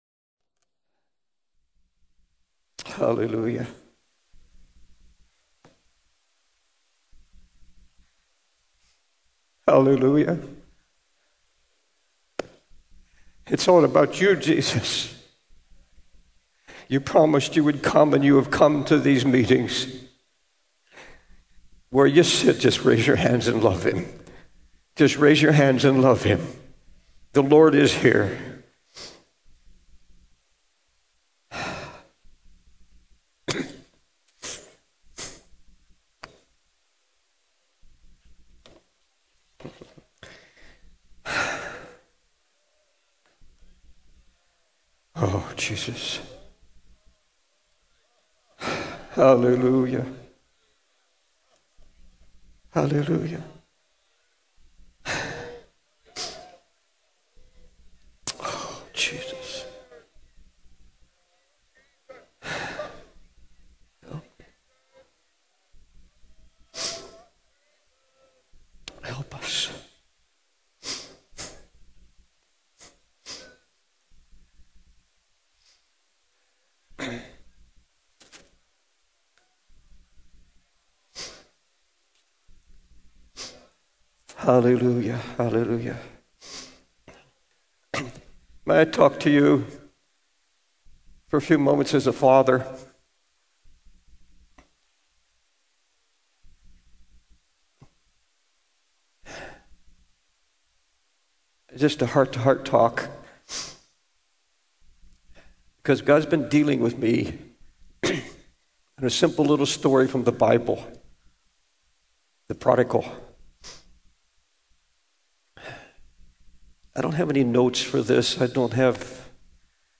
In this sermon, the preacher shares a heart-to-heart talk about the story of the Prodigal Son from the Bible. He emphasizes the importance of knowing and walking in the love of God, as it is the essence of our faith.